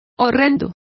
Complete with pronunciation of the translation of horrific.